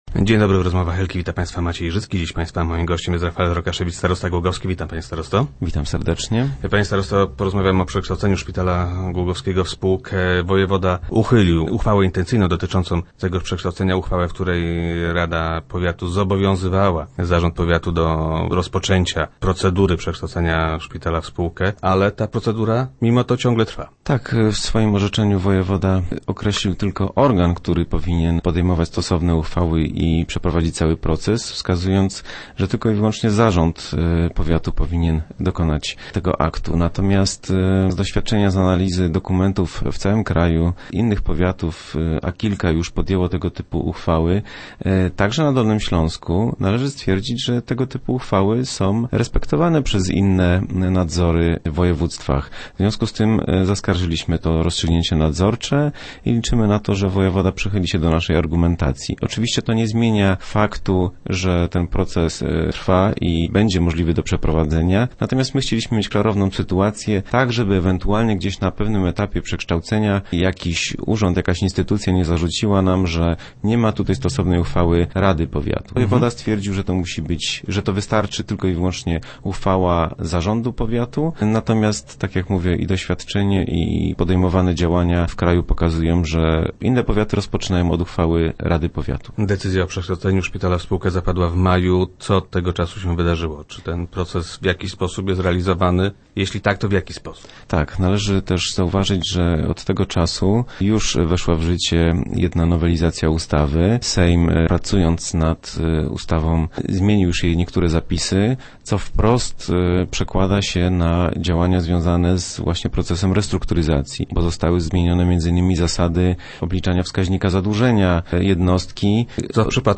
- Wkrótce ogłosimy dwa przetargi – zapowiada starosta Rafael Rokaszewicz, który był gościem Rozmów Elki.